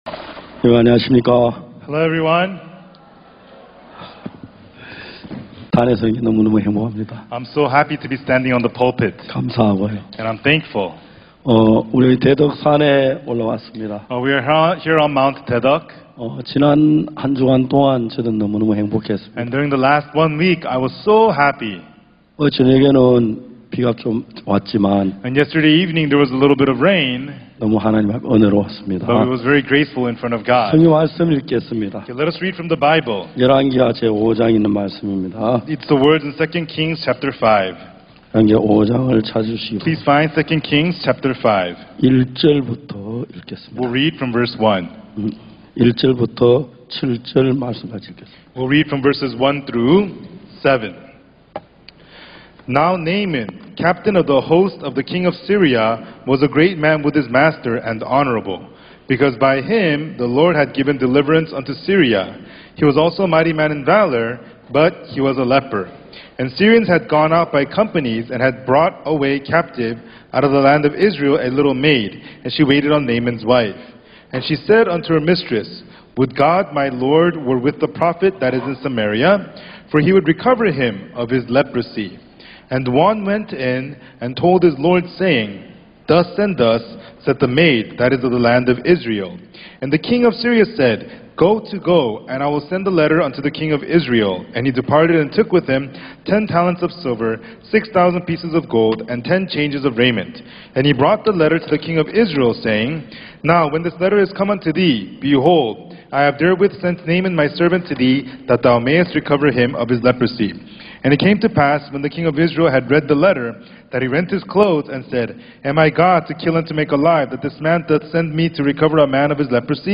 IYF 월드캠프의 주요 프로그램인 마인드 강연. 진정한 삶의 의미를 찾지 못하고 스스로에게 갇혀 방황하는 청소년들에게 어디에서도 배울 수 없는 마음의 세계, 그리고 다른 사람들과 마음을 나누는 방법을 가르친다.